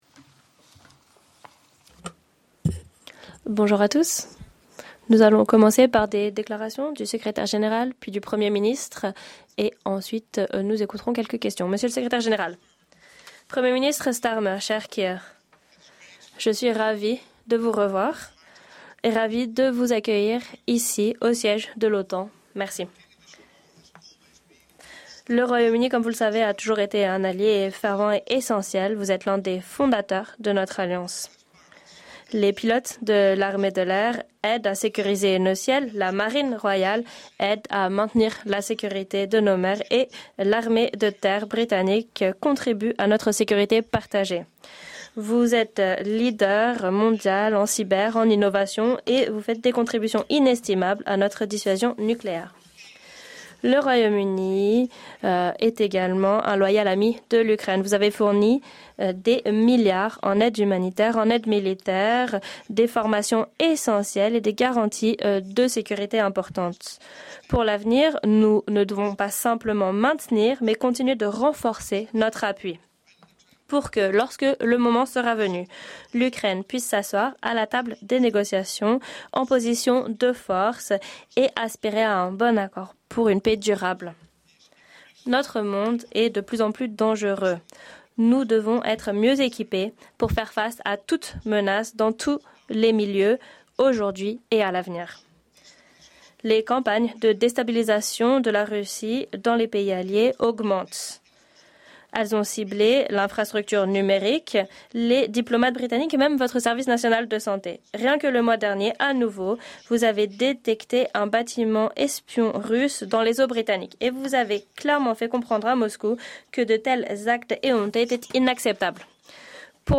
Joint press conference
Joint press conference by NATO Secretary General Mark Rutte with the Prime Minister of the United Kingdom, Keir Starmer